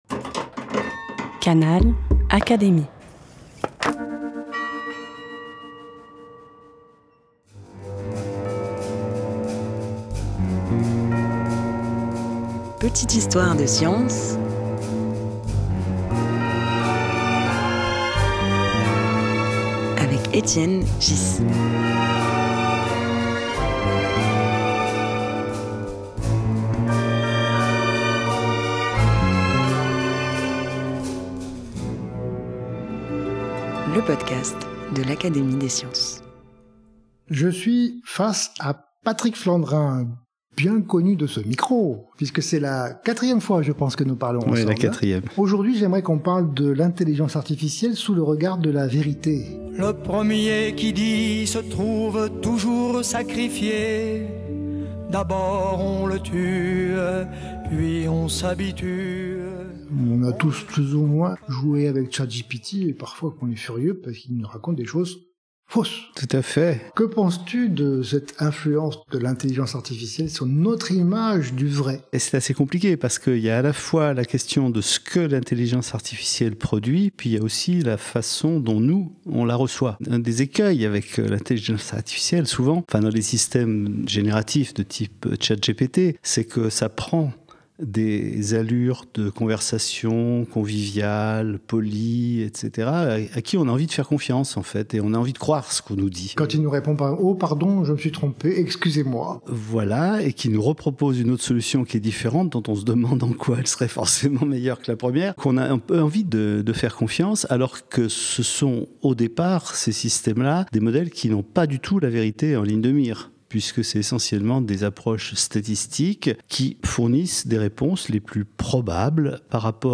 Face à cela, les moteurs de recherche classiques conservent un atout : ils renvoient à des sources identifiables et vérifiables, tout en restant moins gourmands en énergie. Une conversation stimulante sur les illusions, les promesses… et les limites de l’IA.